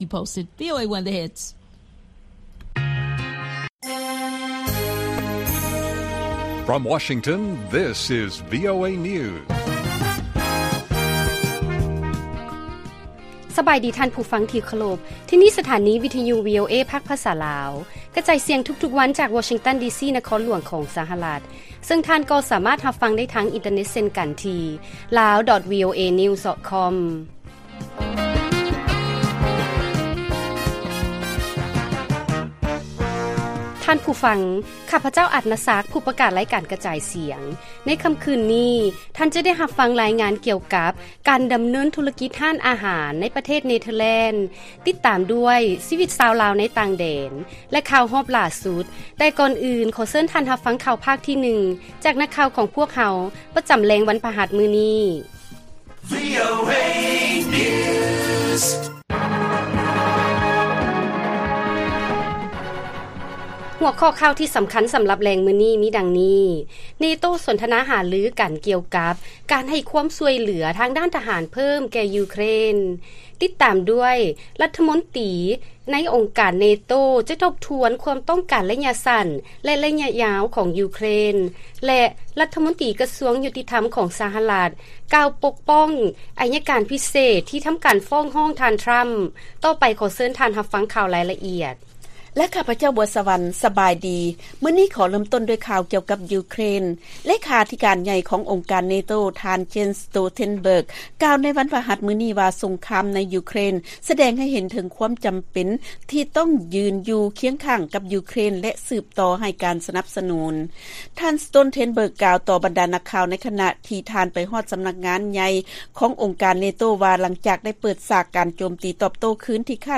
ລາຍການກະຈາຍສຽງຂອງວີໂອເອ ລາວ: ເນໂຕ້ສົນທະນາຫາລືກັນ ກ່ຽວກັບການໃຫ້ຄວາມຊ່ວຍເຫລືອ ທາງທະຫານແກ່ຢູເຄຣນເພີ້ມ